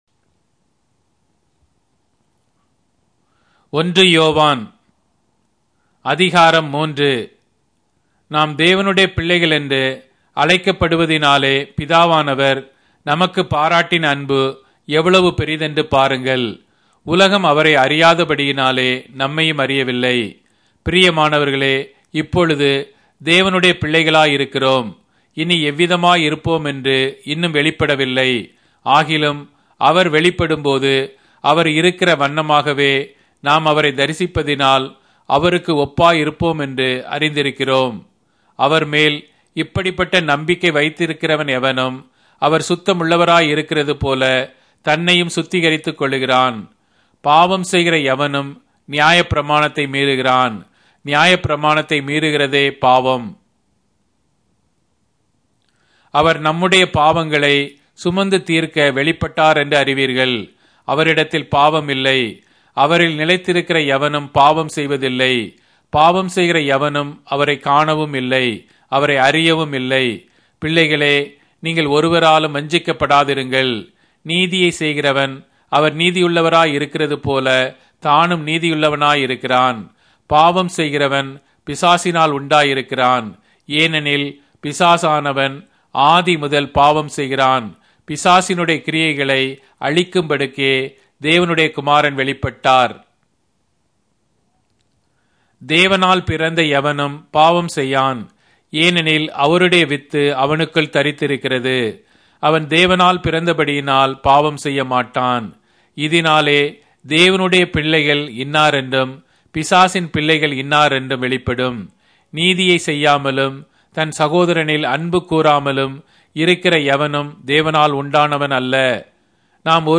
Tamil Audio Bible - 1-John 1 in Irvhi bible version